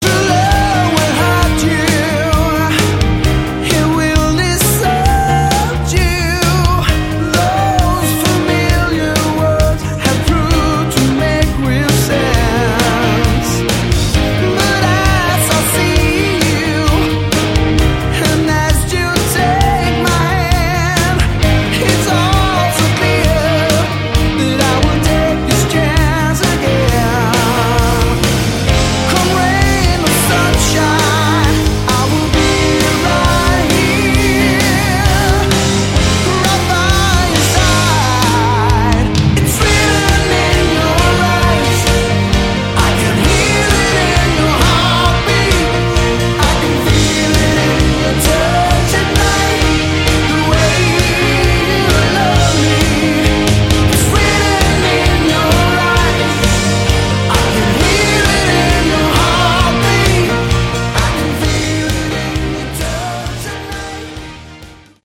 Category: AOR
Drums
Keyboards, Backing Vocals
Guitars, Backing Vocals
Lead Vocals
Bass, Backing Vocals
Very Melodic.